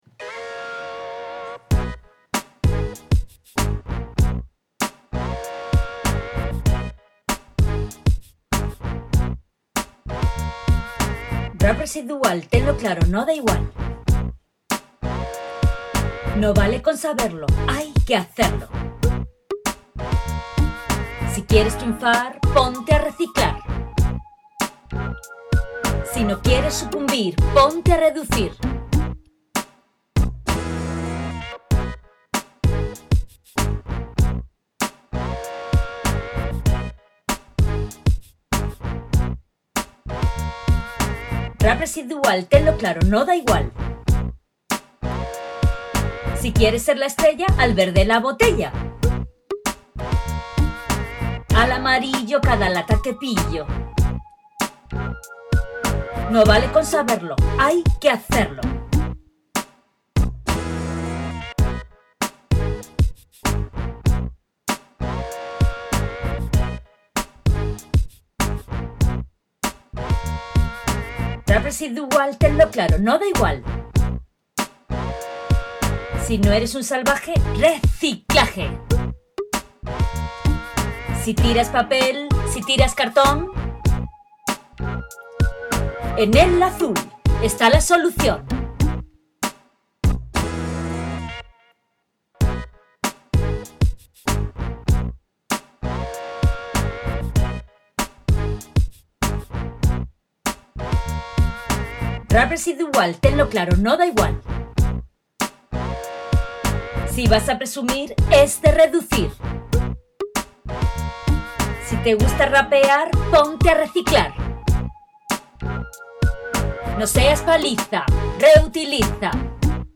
RAP-RESIDUAL-OK.mp3